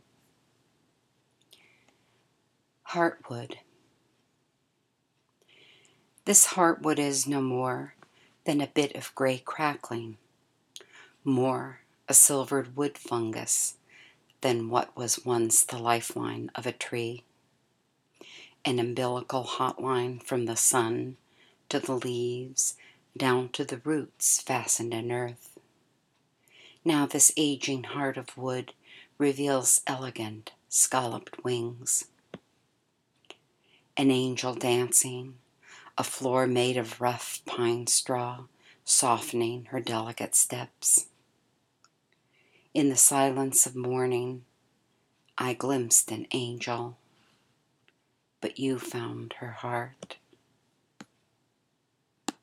I’ve recorded an audio of me reading this poem.